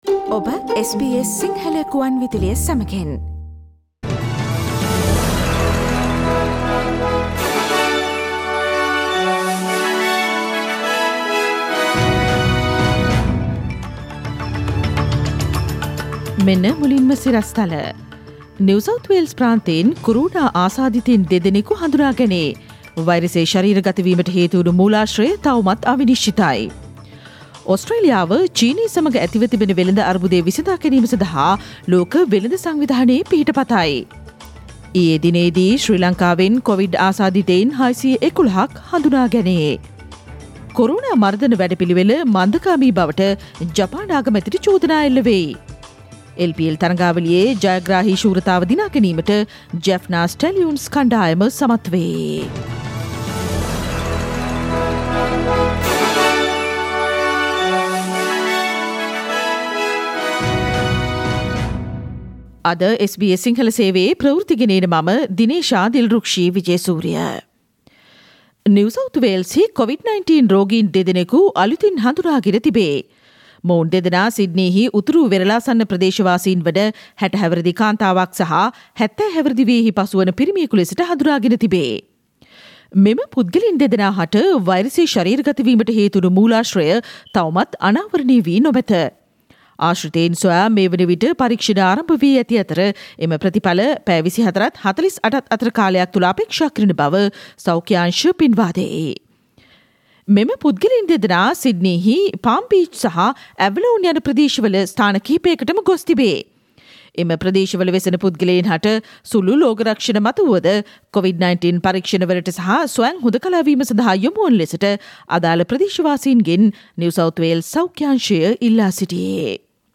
Today’s news bulletin of SBS Sinhala radio – Thursday 17 December 2020